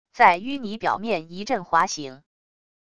在淤泥表面一阵滑行wav音频